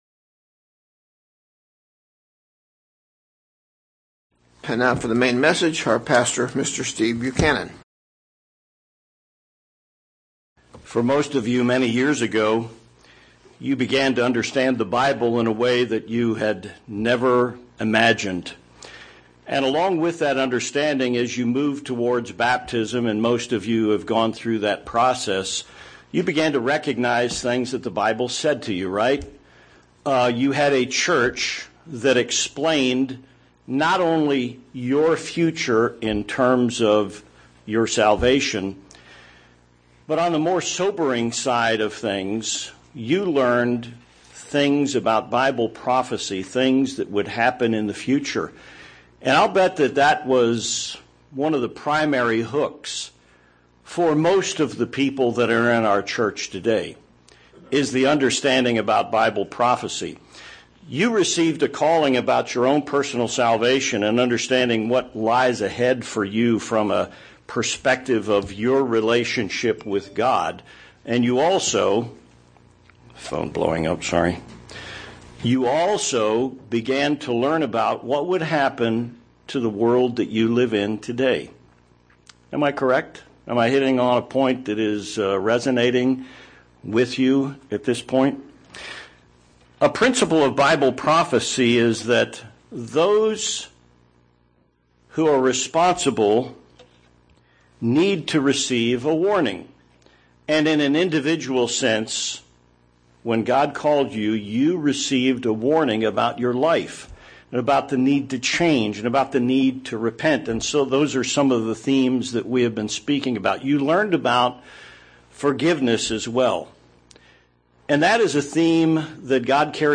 Should the Church comment on the actions of national leaders or governments? This sermon notes the relationship on human governments, and the check and balance God provided through the office of the Prophets.